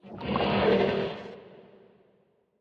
Minecraft Version Minecraft Version 1.21.5 Latest Release | Latest Snapshot 1.21.5 / assets / minecraft / sounds / mob / horse / skeleton / water / idle2.ogg Compare With Compare With Latest Release | Latest Snapshot